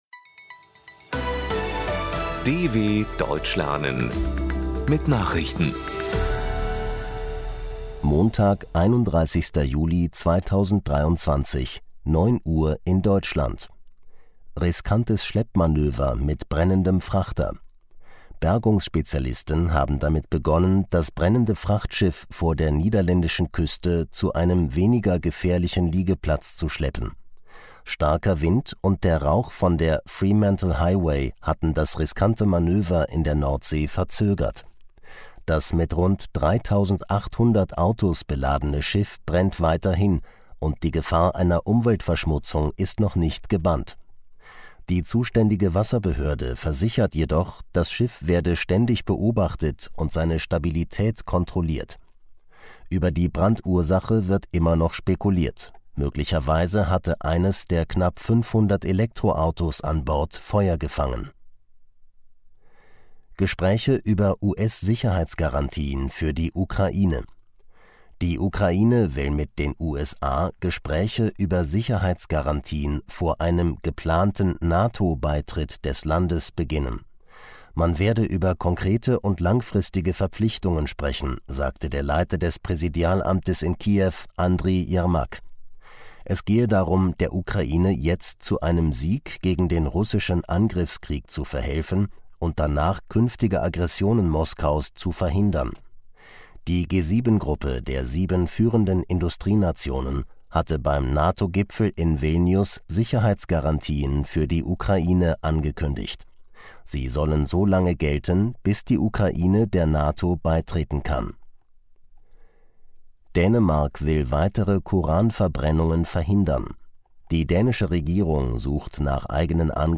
31.07.2023 – Langsam Gesprochene Nachrichten
Trainiere dein Hörverstehen mit den Nachrichten der Deutschen Welle von Montag – als Text und als verständlich gesprochene Audio-Datei.